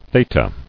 [the·ta]